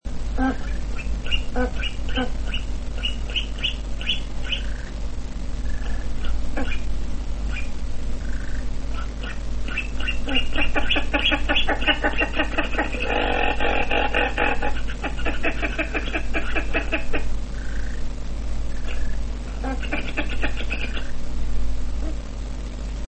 głosy innych kazarek
kazarka (Casarca) nadobna - Tadorna Radjahmp390 kb